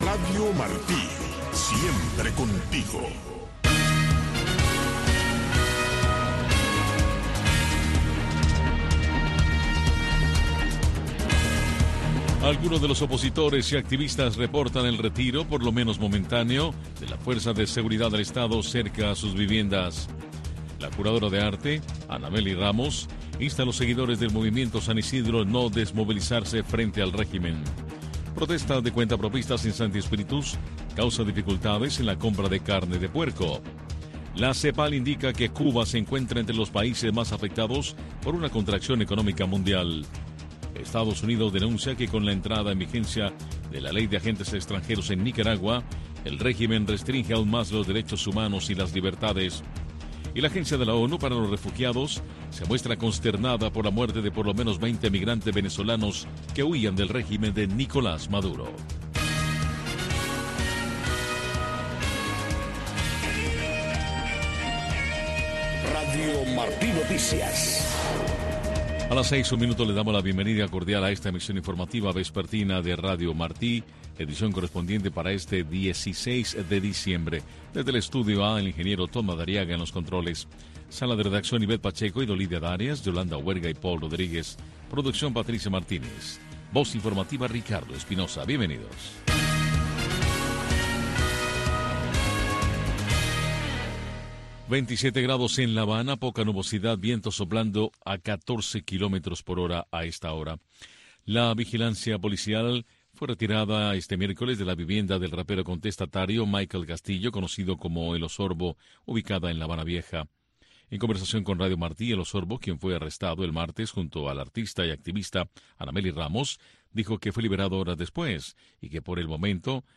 Noticiero de Radio Martí 5:00 PM